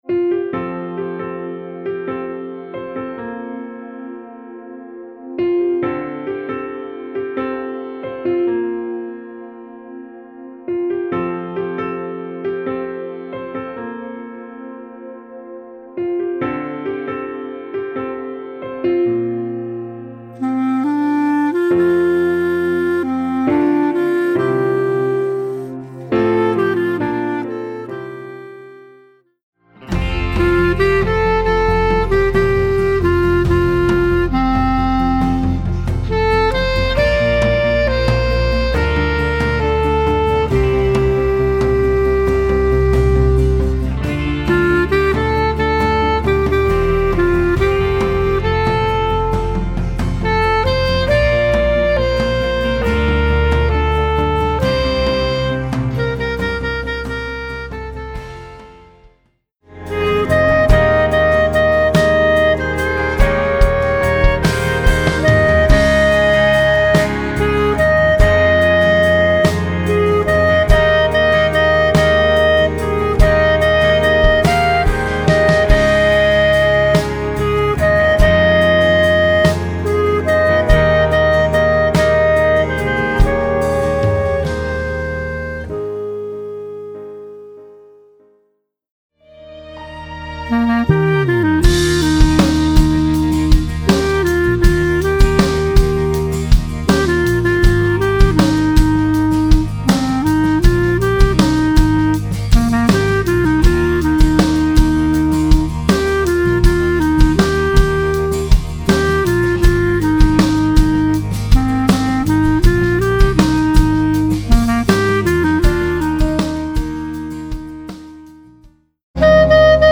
Voicing: Clarinet